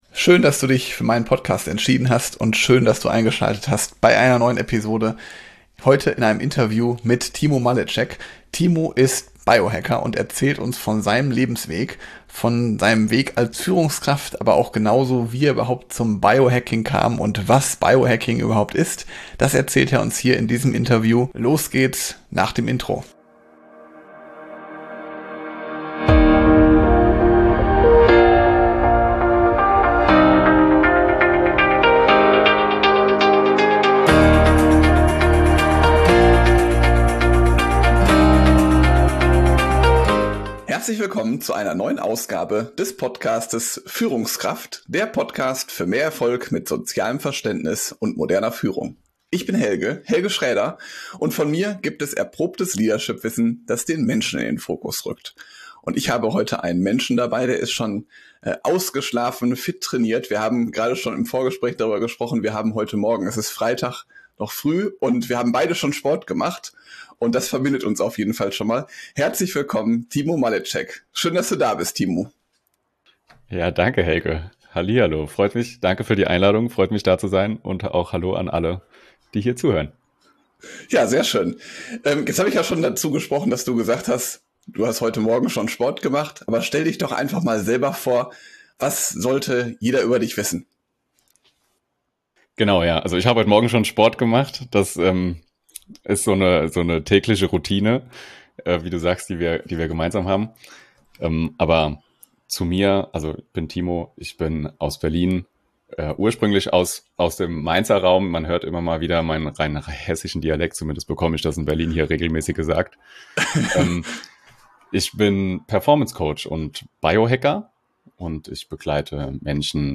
Tu Deinem Körper etwas Gutes - Interview